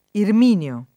[ irm & n L o ]